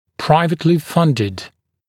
[‘praɪvɪtlɪ ‘fʌndɪd][‘прайвитли ‘фандид]финансируемый из личных источников